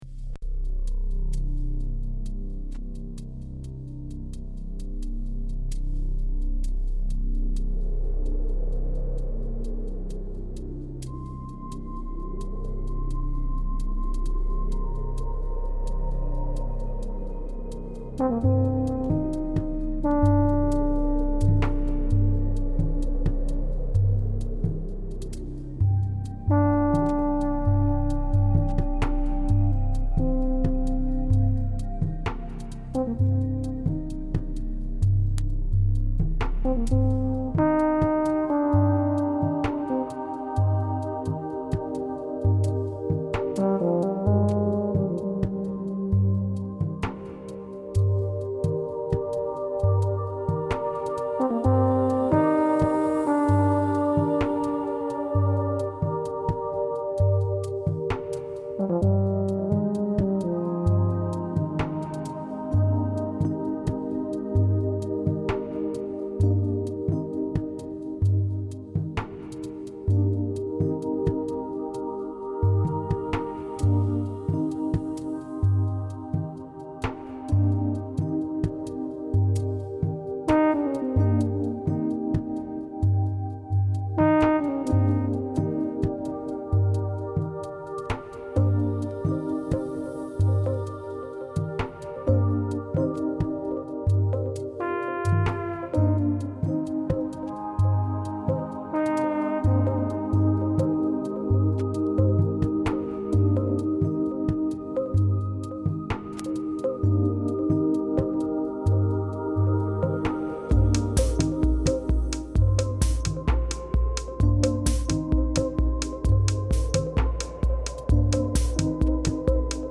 synths, sampled piano, samples, guitars and programming